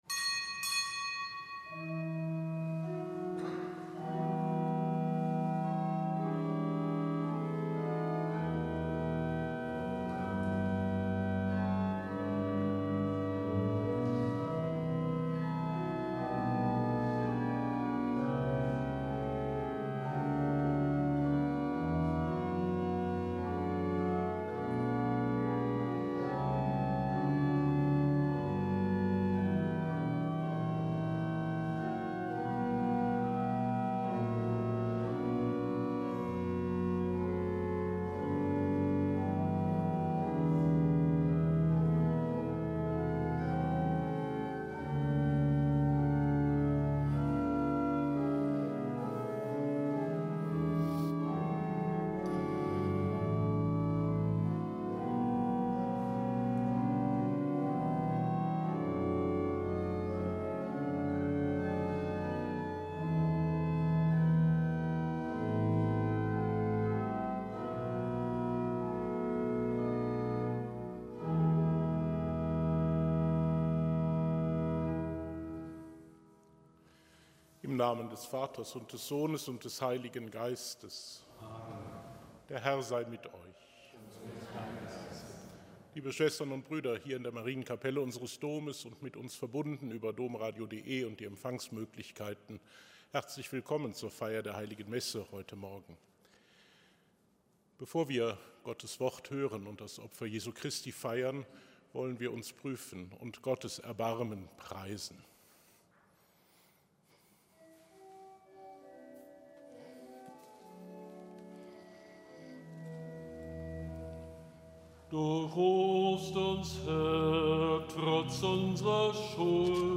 Kapitelsmesse aus dem Kölner Dom am Mittwoch der ersten Fastenwoche.